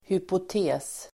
Uttal: [hypot'e:s]